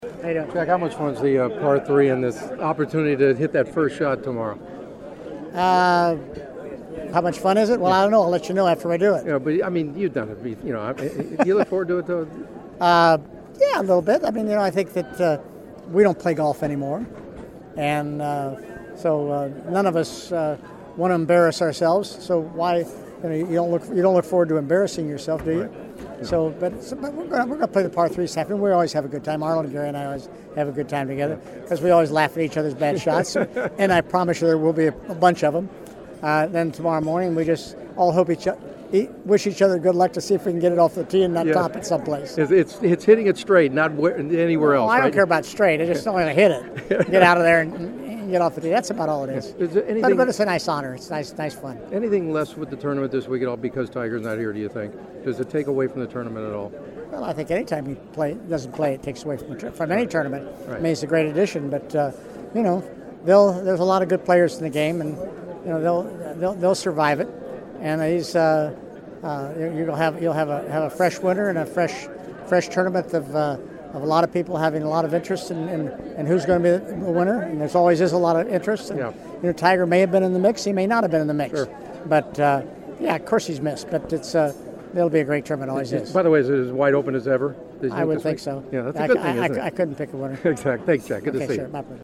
The following are some of my Masters preview interviews leading into Thursday’s first round.
on Wednesday at the Masters